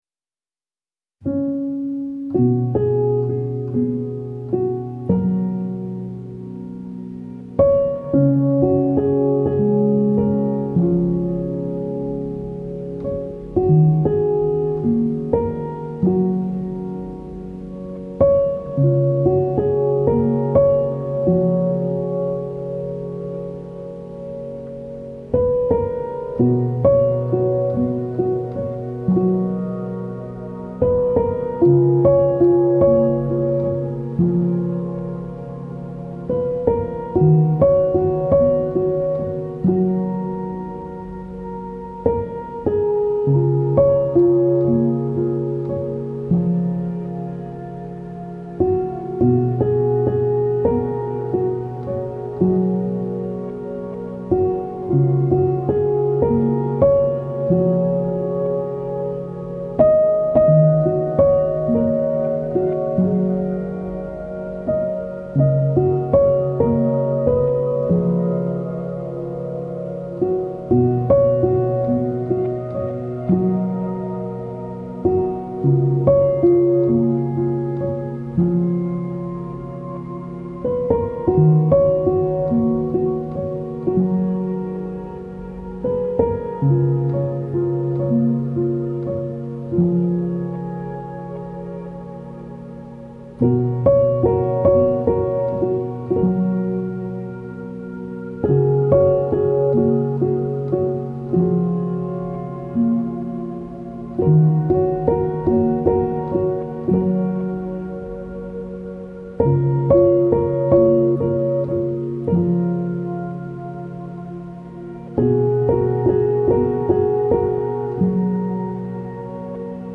آرامش بخش
Classical Crossover
پیانو , عمیق و تامل برانگیز